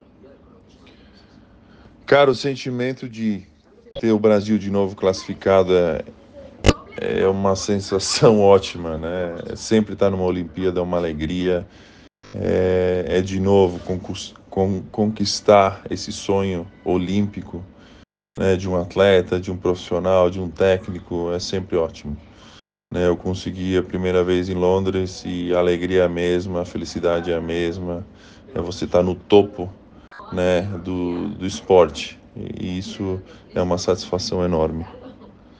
Entrevista com Tiago Splitter – Auxiliar técnico da Seleção Brasileira de Basquete